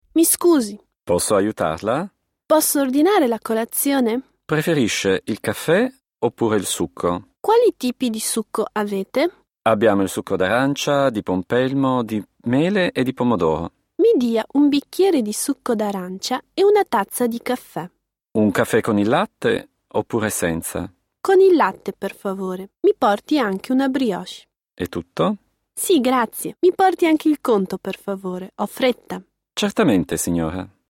W restauracji - dialog
wloski_dialog2.mp3